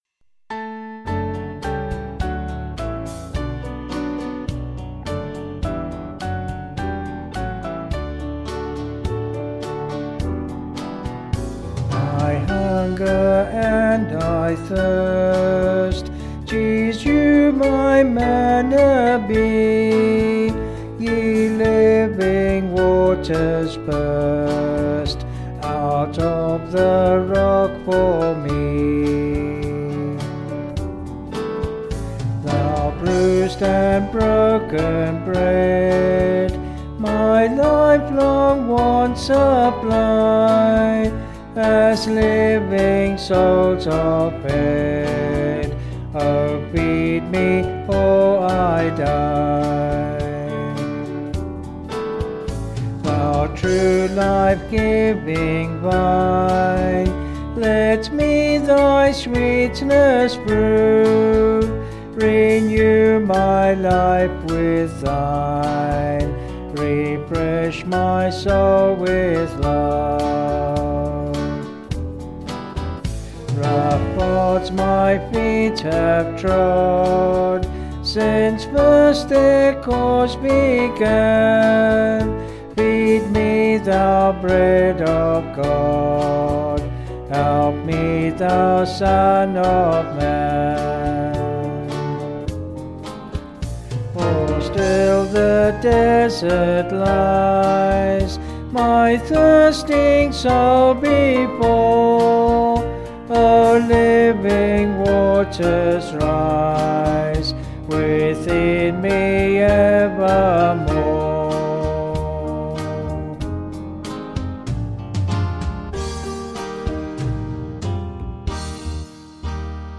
Vocals and Band   263.7kb Sung Lyrics